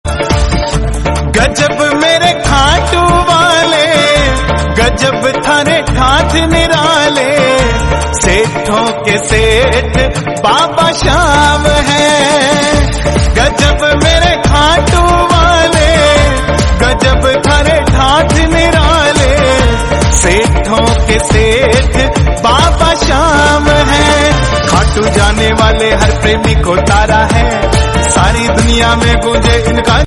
Category Devotional